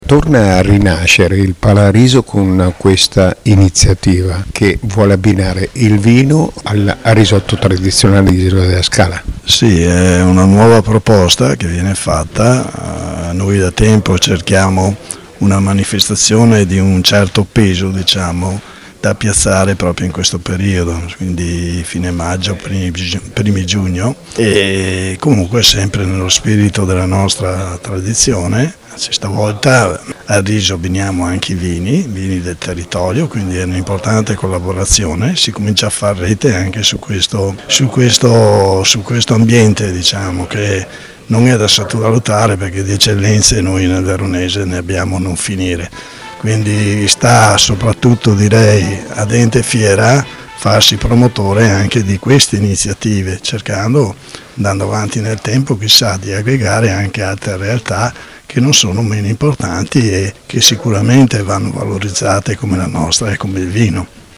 Al microfono del nostro corrispondente
il sindaco di Isola della Scala, Luigi Mirandola